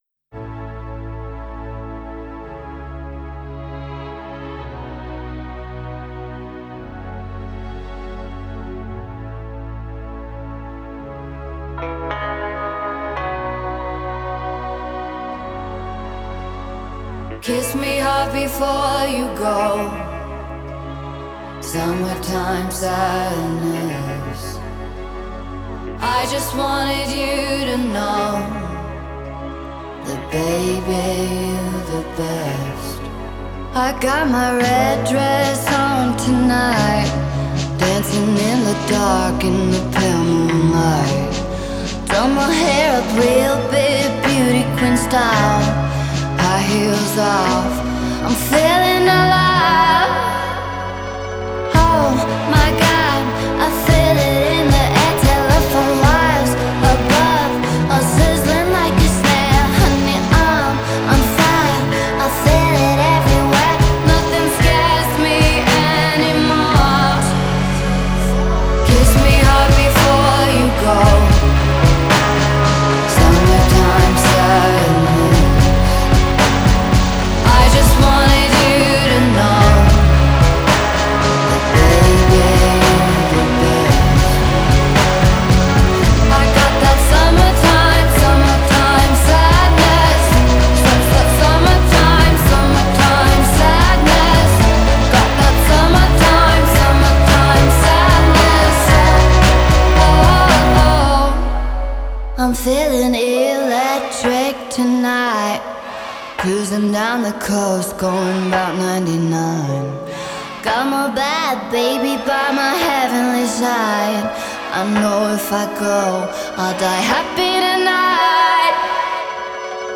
فضای ملانکولیک